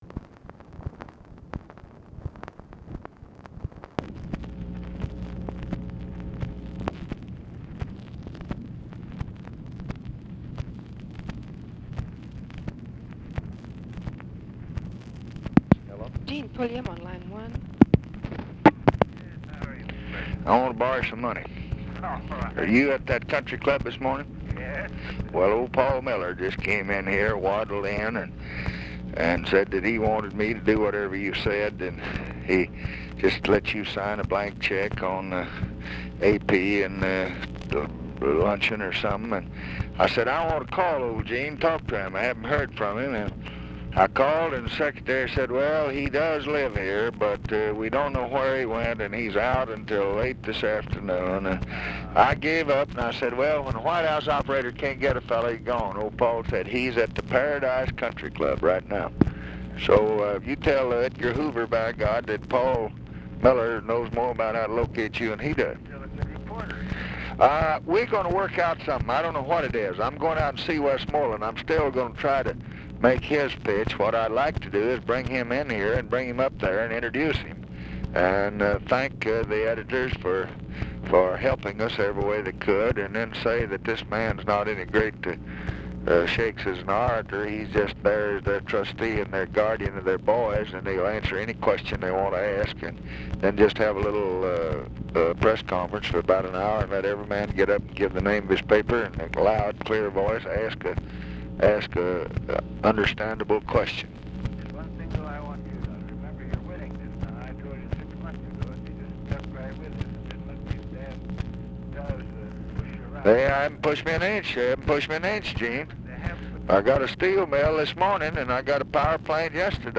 Conversation with EUGENE PULLIAM, March 11, 1967
Secret White House Tapes